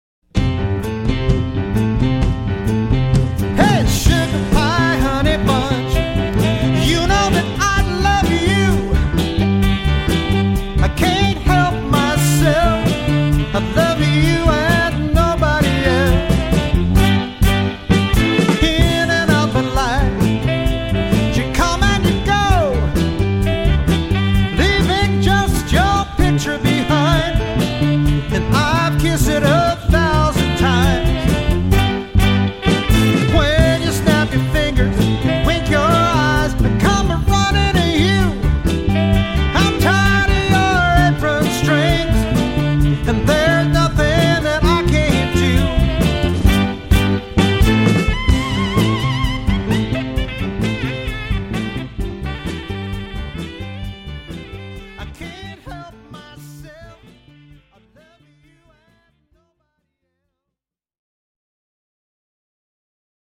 Dance Band with Vocalists
Motown with vocalist